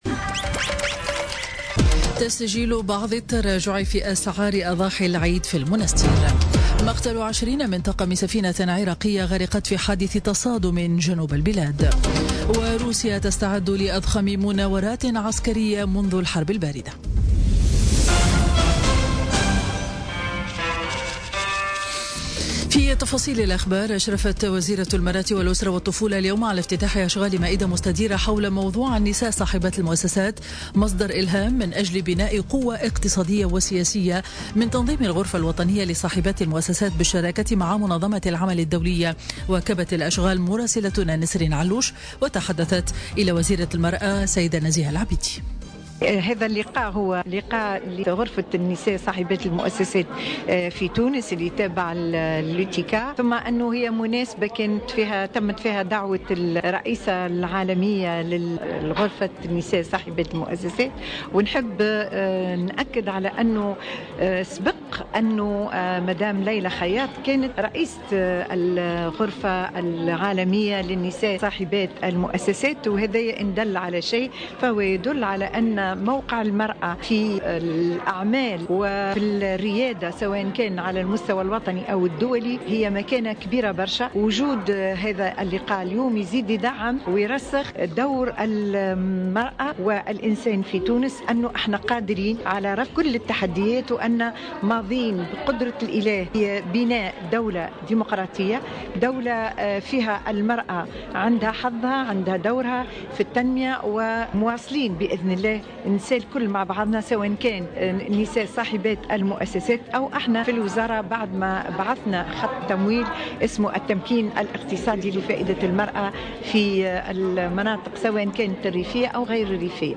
نشرة أخبار منتصف النهار ليوم الخميس 24 أوت 2017